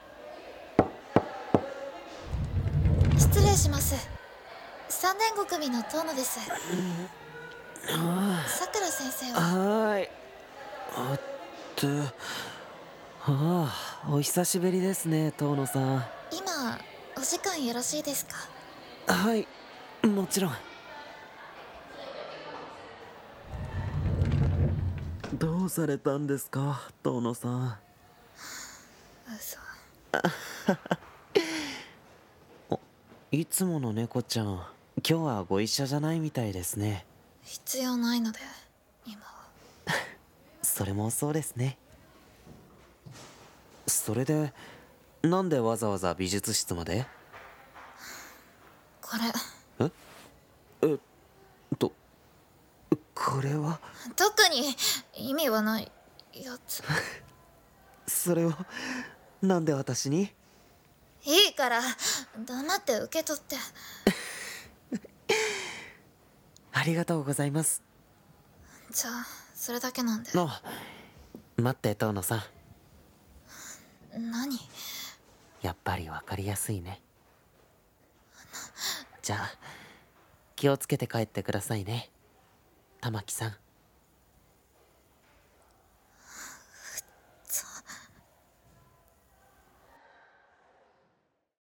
【二人声劇】25g、秘す音